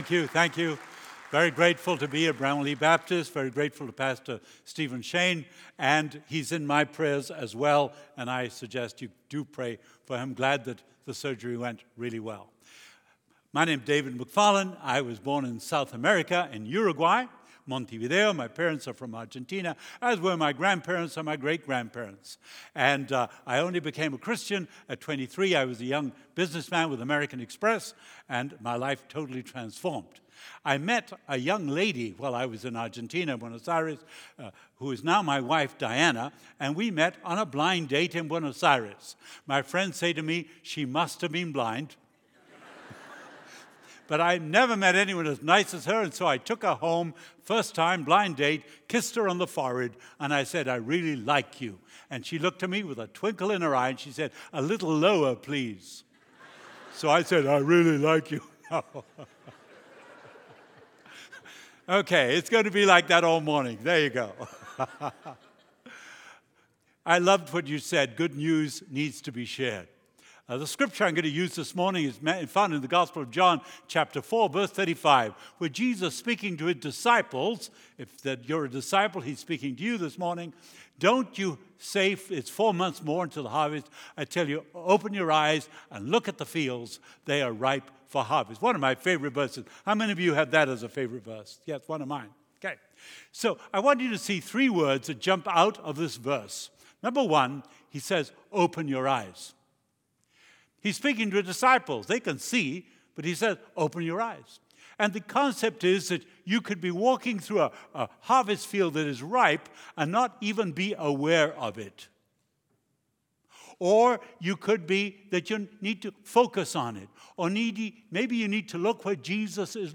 Sermons | Bramalea Baptist Church